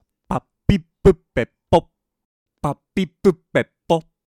今日入手したポップガードの音声比較。